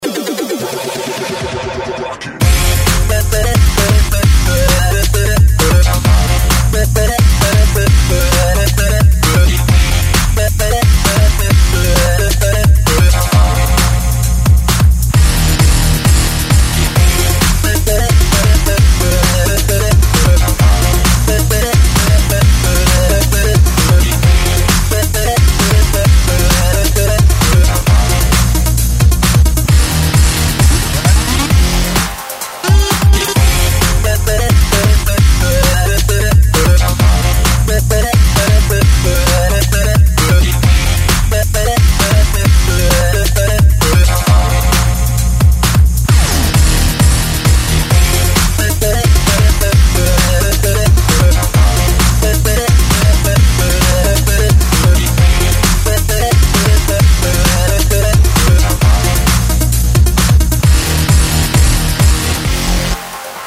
• Качество: 128, Stereo
break beat